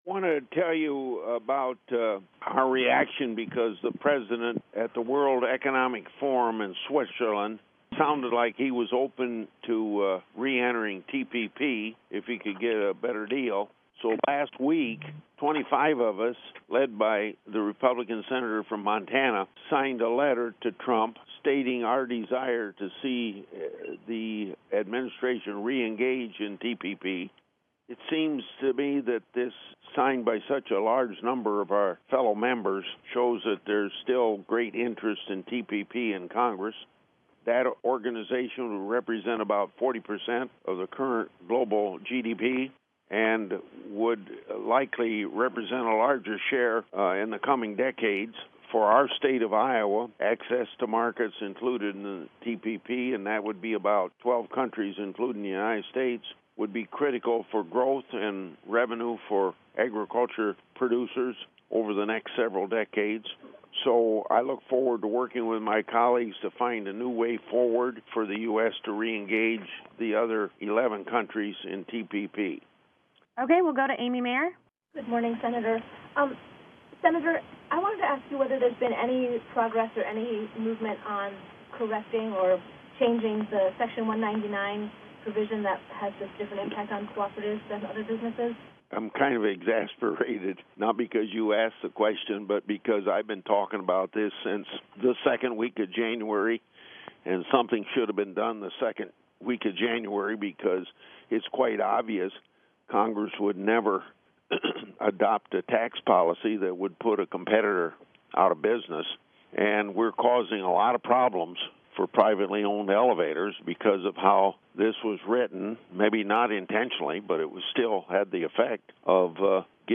Farm Broadcasters' Call